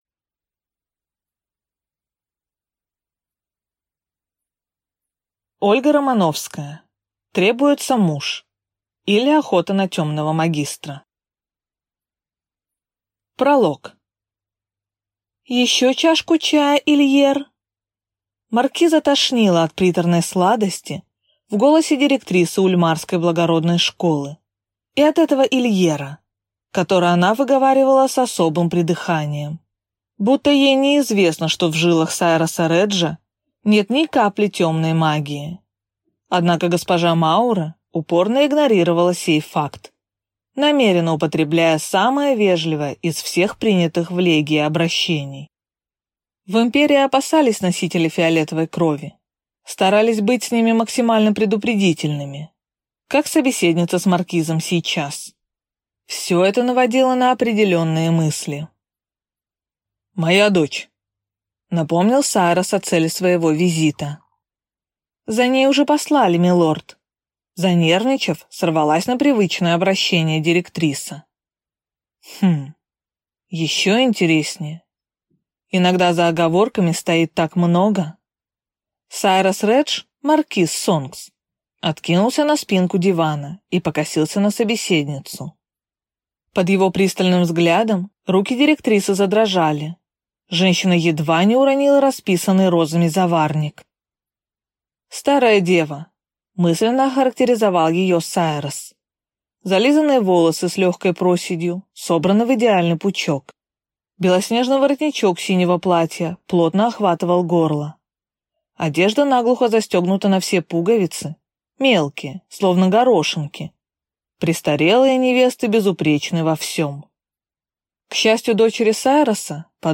Аудиокнига Требуется муж, или Охота на темного магистра | Библиотека аудиокниг